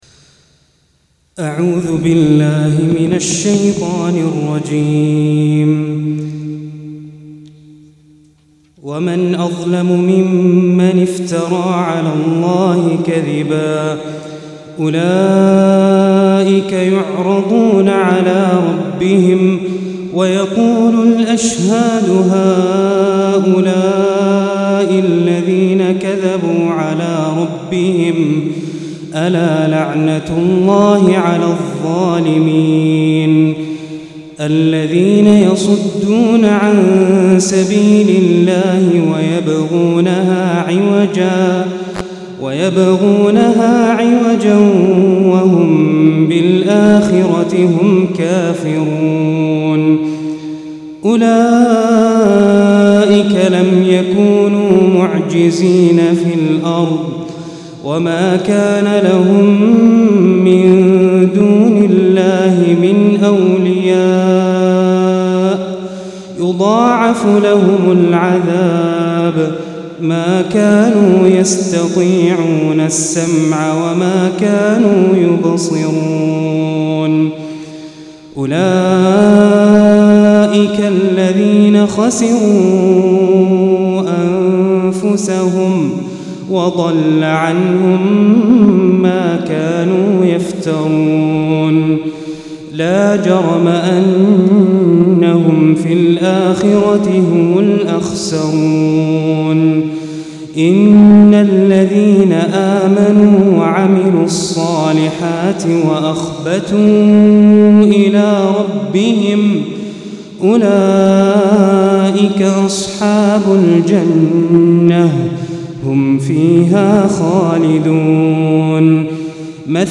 212- عمدة التفسير عن الحافظ ابن كثير رحمه الله للعلامة أحمد شاكر رحمه الله – قراءة وتعليق –